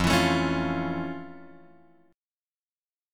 F Augmented Major 7th